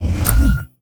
delete_timeline.ogg